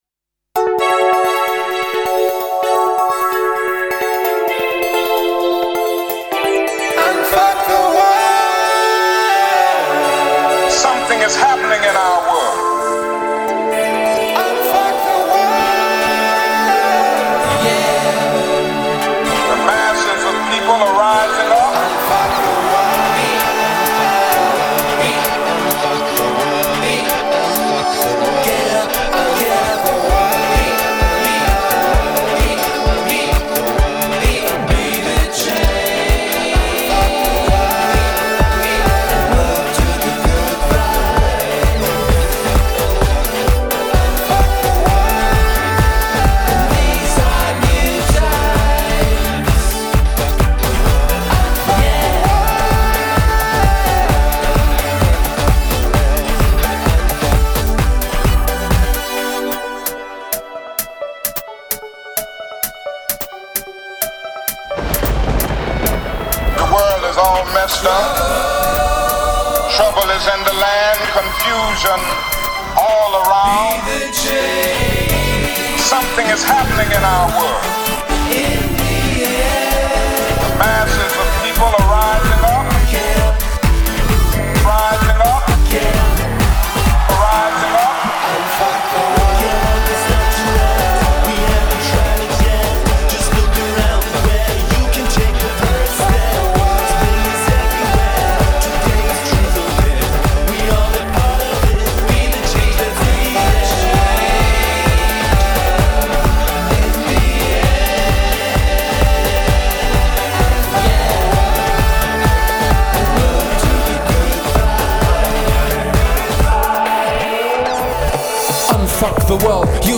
Hymne geschrieben.